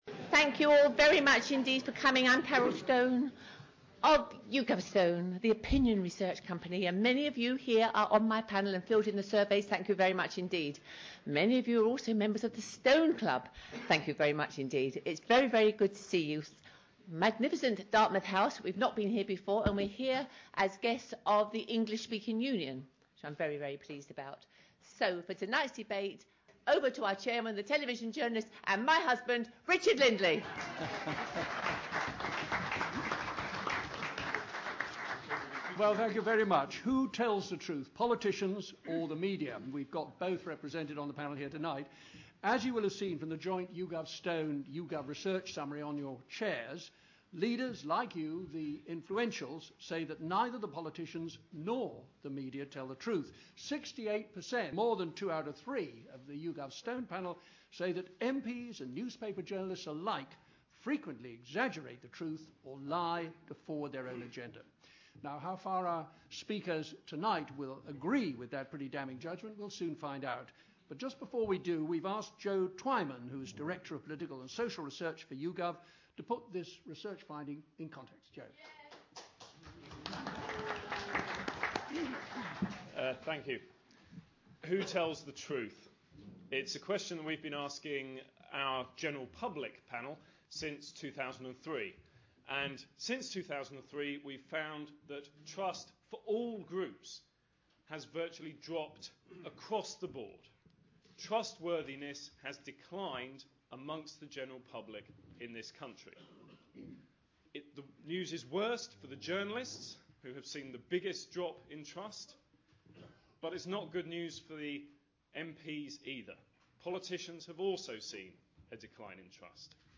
On October 14th 2010, YouGovStone hosted a debate on the issue of trust and truth in media and politics. The debate focused on who was to blame, how to correct it and whether the public was more complicit in the situation than had first been suggested.